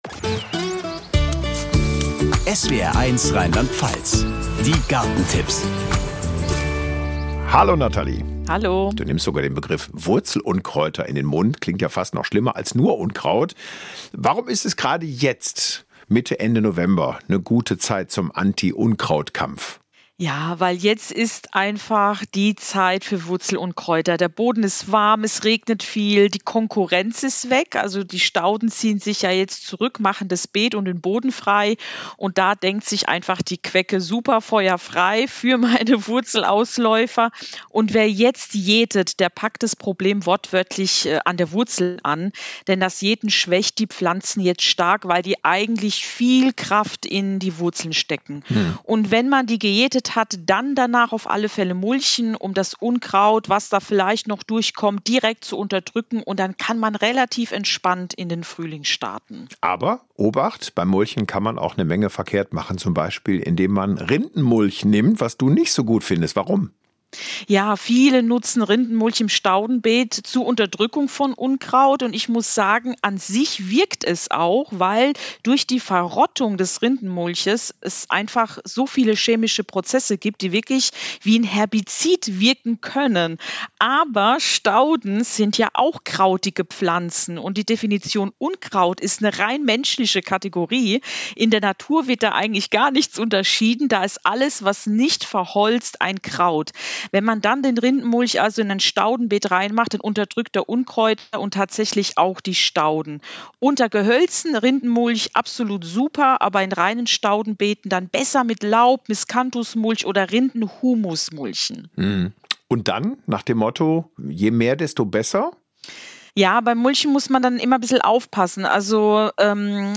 Das Gespräch führte
Gespräch mit